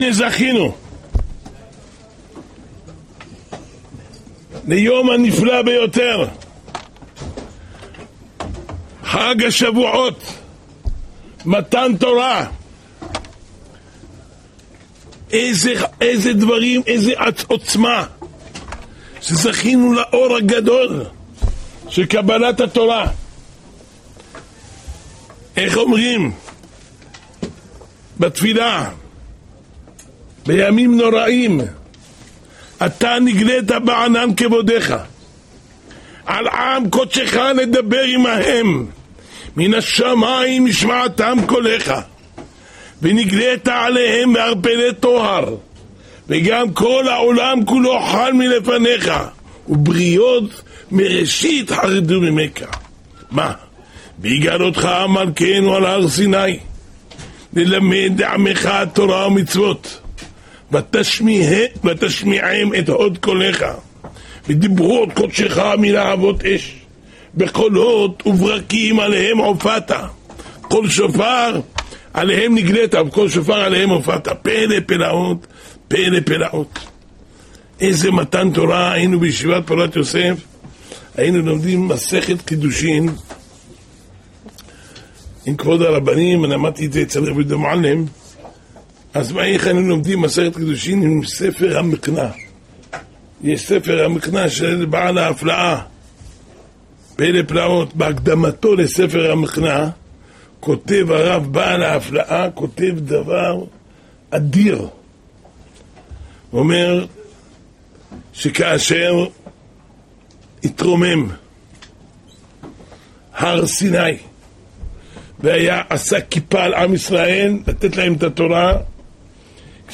השיעור השבועי